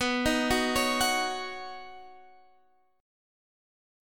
Badd9 Chord
Listen to Badd9 strummed